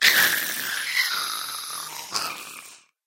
Sound / Minecraft / mob / ghast / death.ogg